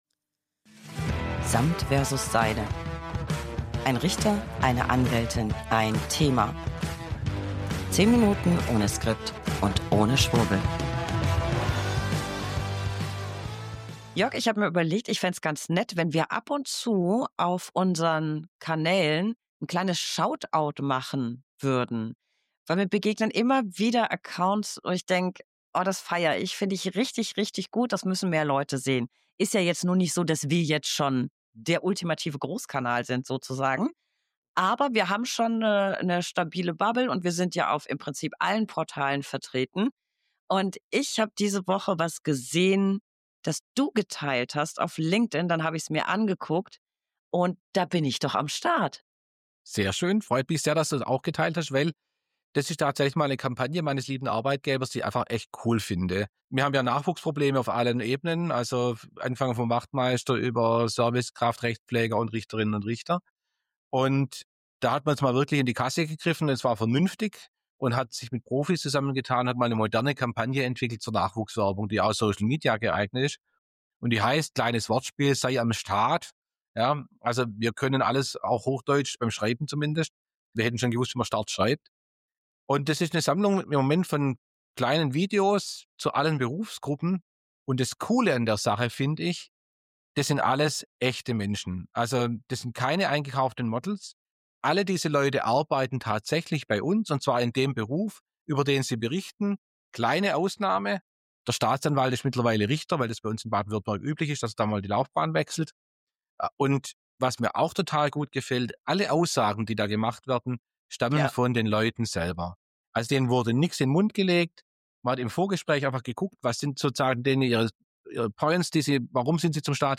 1 Anwältin + 1 Richter + 1 Thema.
Manchmal auch weniger als 10 Minuten ohne Skript und ohne